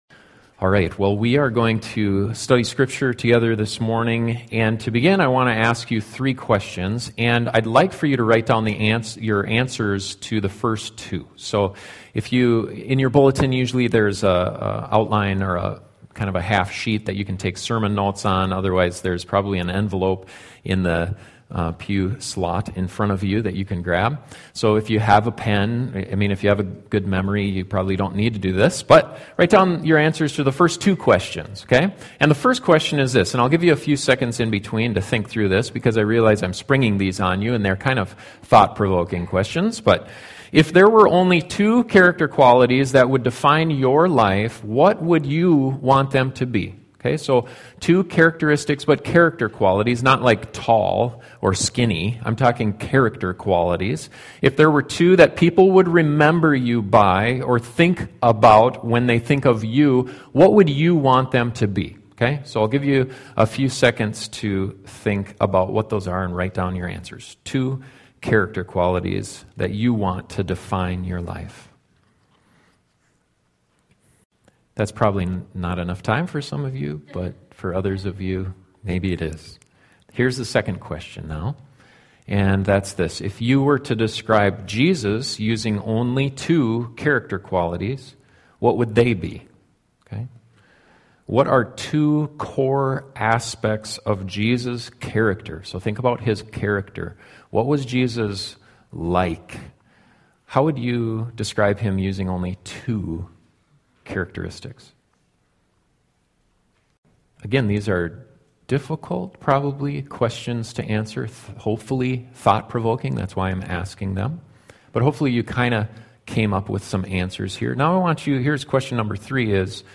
As we seek to become more like Jesus, our character should align more and more with his. This sermon explores two core character qualities to strive for in order to […]